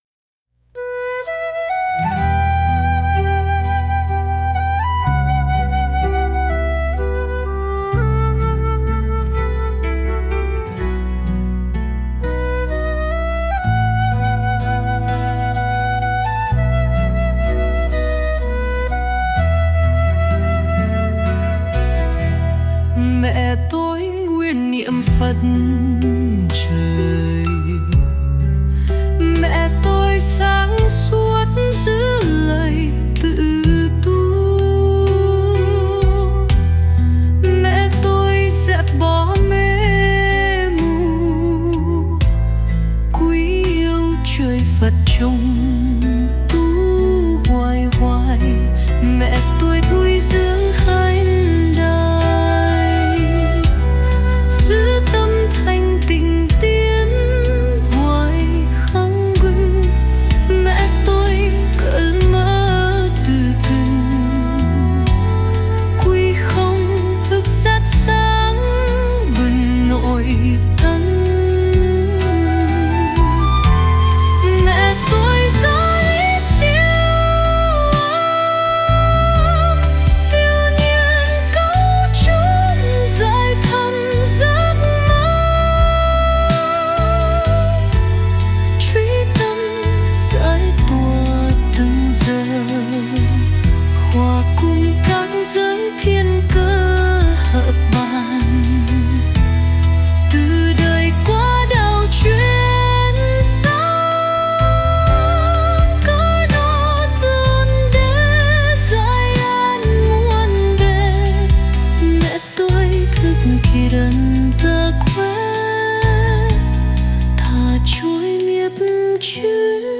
118-Me-toi-01-nu-cao.mp3